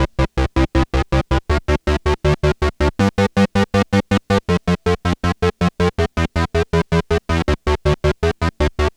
Track 16 - Arp 03.wav